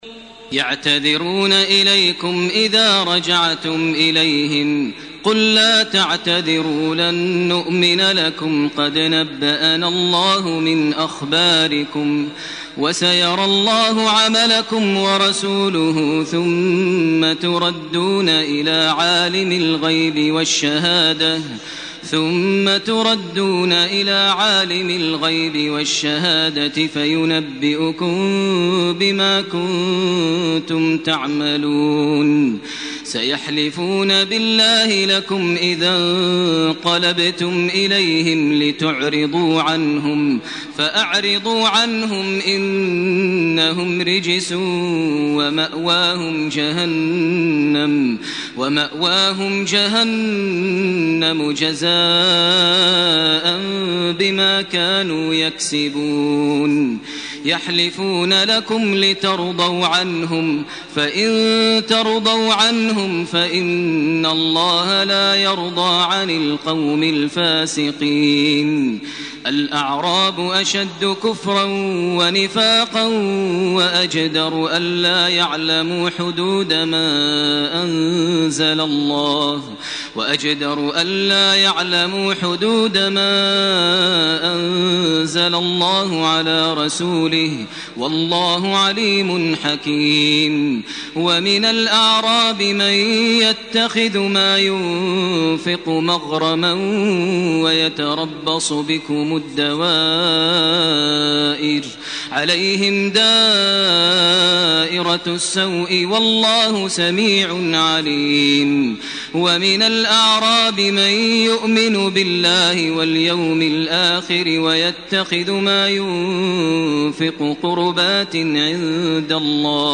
سورة التوبة 94 الي اخرها سورة يونس 1 -25 > تراويح ١٤٢٨ > التراويح - تلاوات ماهر المعيقلي